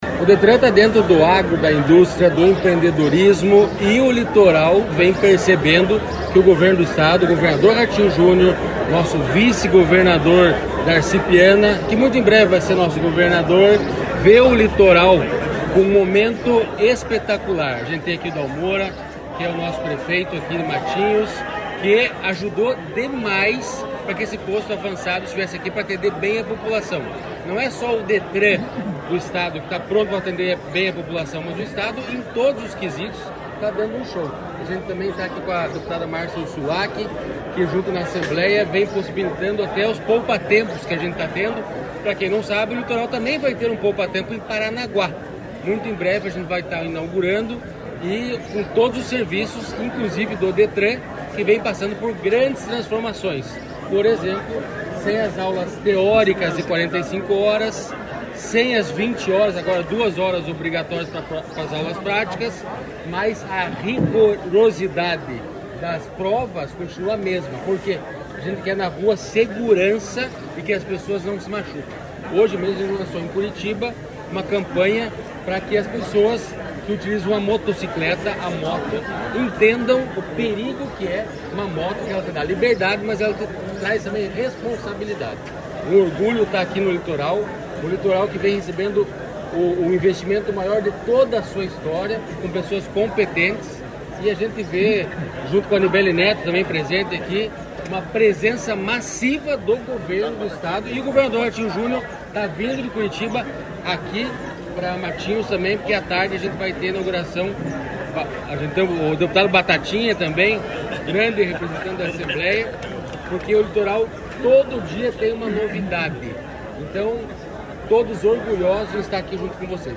Sonora do diretor-presidente do Detran, Santin Roveda, sobre o novo Posto Avançado do Detran